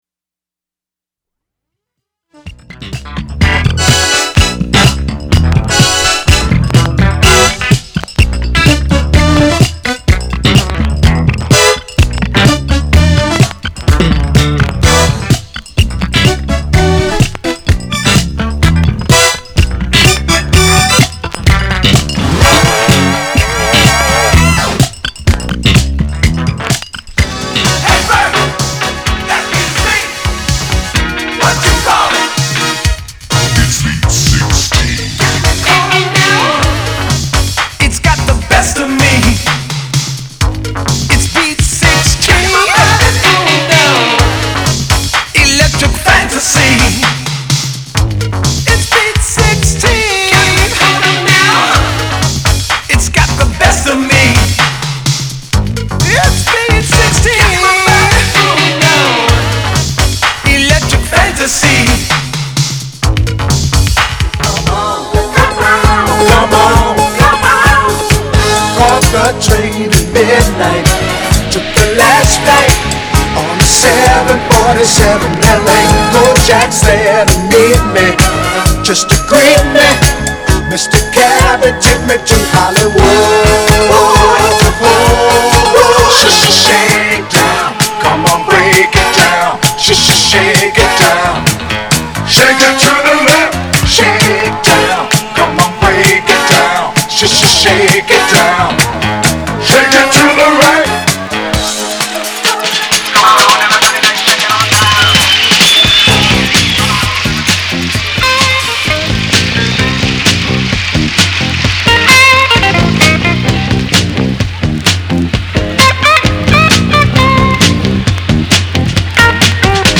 category Disco